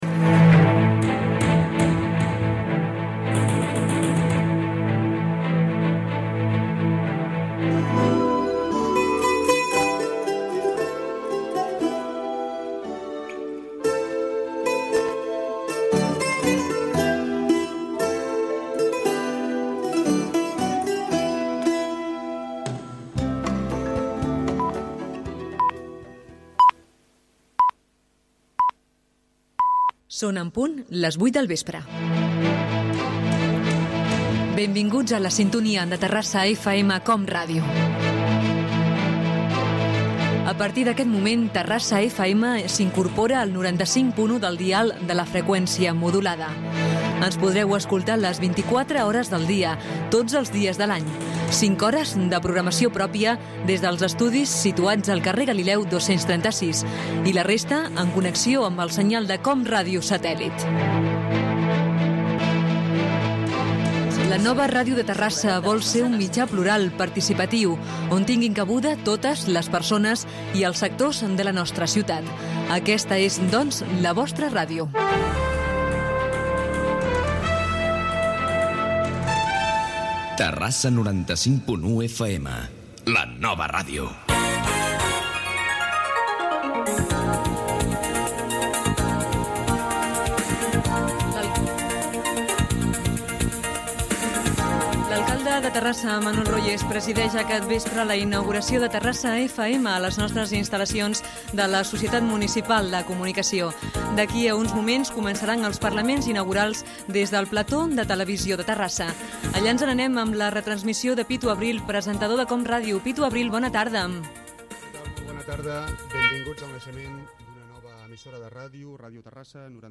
Hora, estrena de les emissions, indicatiu, connexió amb el plató de Televisió de Terrassa, comentari incial i discursos de Jaume Canyameras, regidor de Comunicació, i l'alcalde Manuel Royes
Primer dia d'emissió de la ràdio municipal de Terrassa.